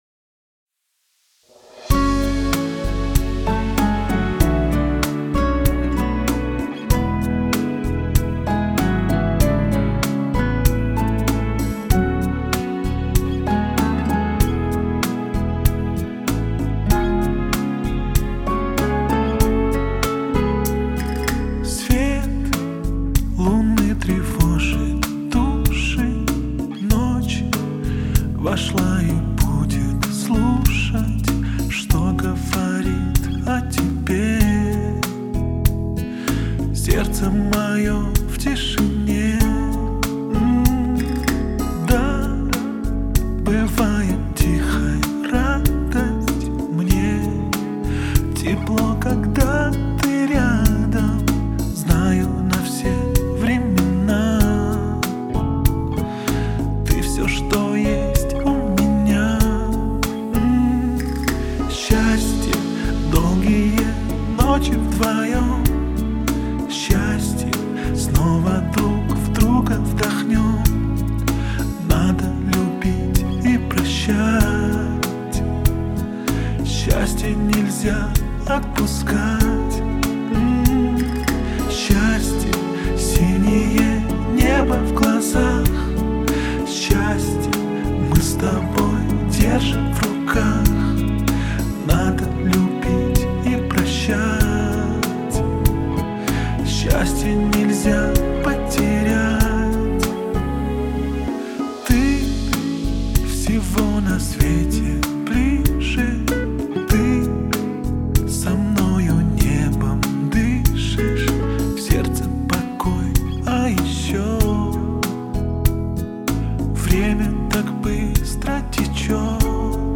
это трогательный и мелодичный трек в жанре поп.